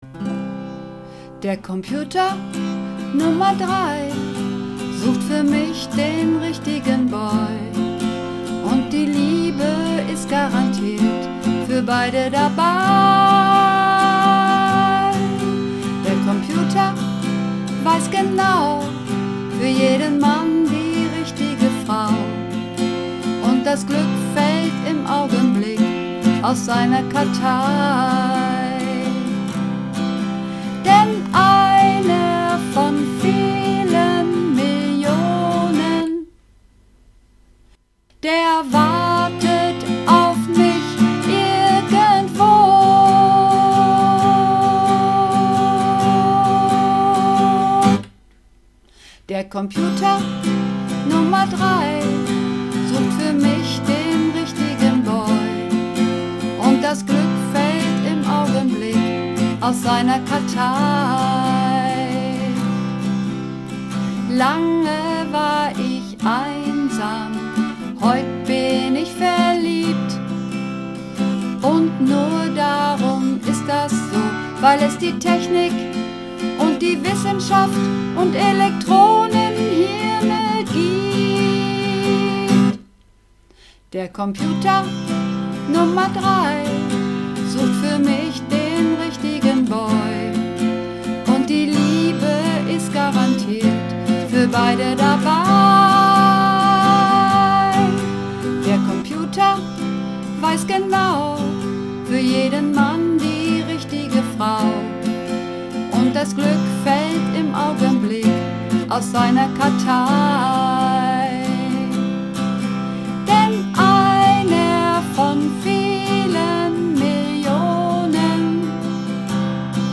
Übungsaufnahmen - Der Computer Nr. 3
Der Computer Nr. 3 (Sopran)
Der_Computer_Nr_3__3_Sopran.mp3